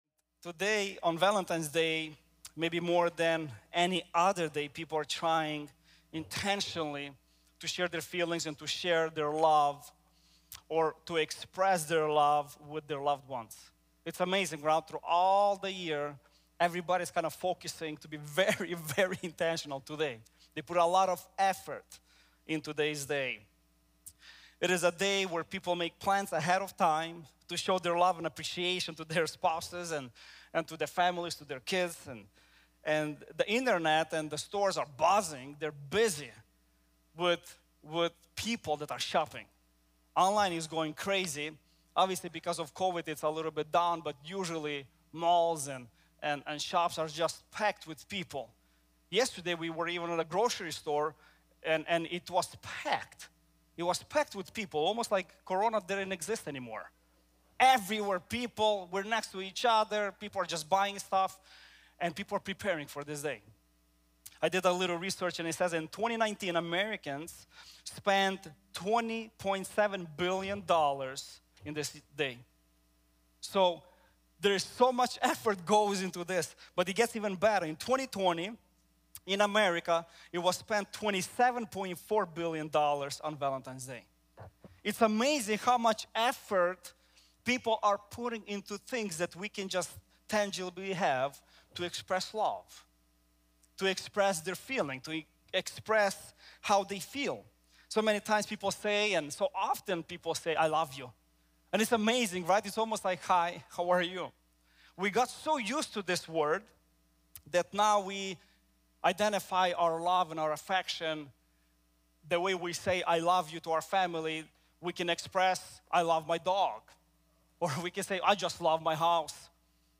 Agape Love | Times Square Church Sermons